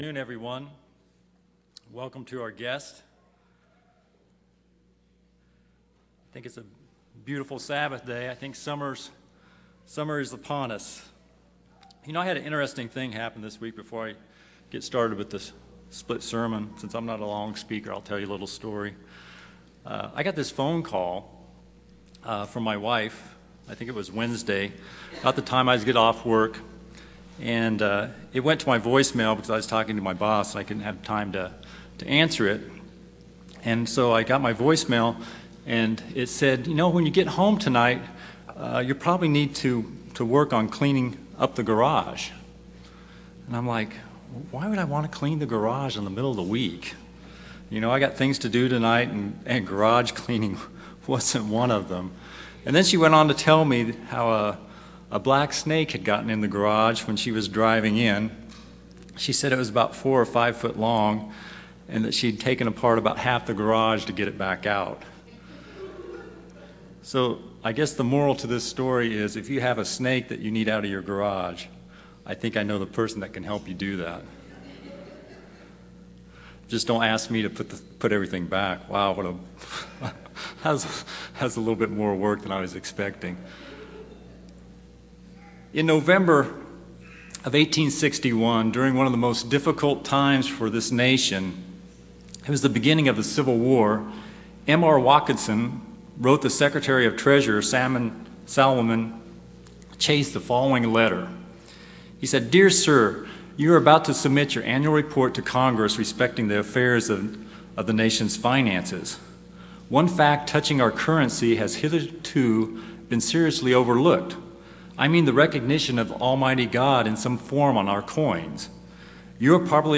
UCG Sermon Notes These are the speaker’s notes.